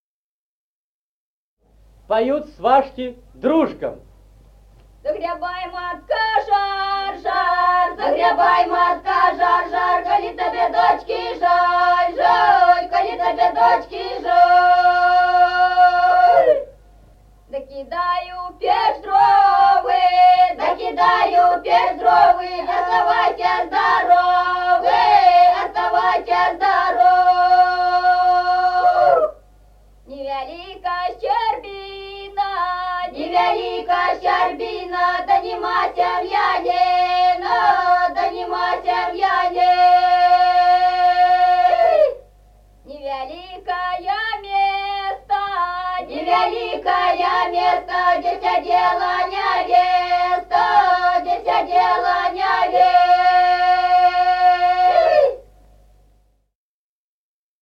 Народные песни Стародубского района «Загребай, матка, жар», свадебная, свашки поют дру́жкам.
(подголосник)
(запев).